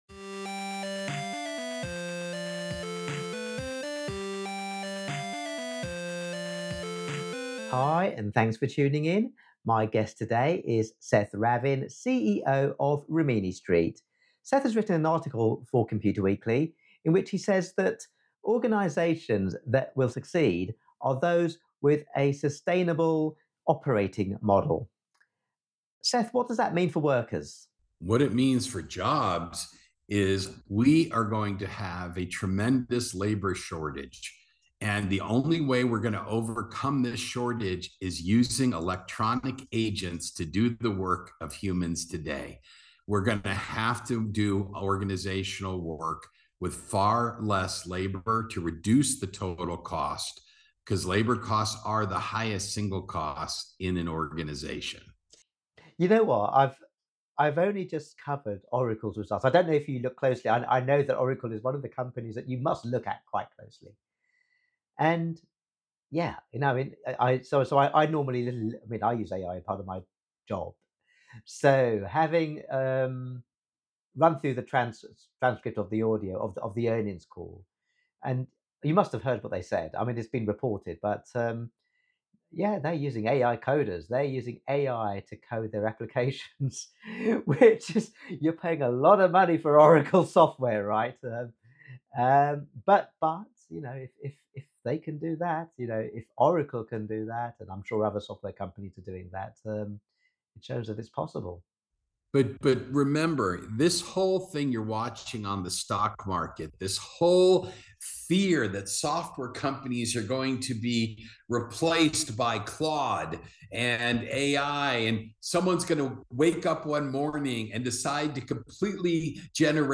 In this podcast interview